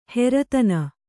♪ heratana